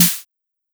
Tron Cat Snare.wav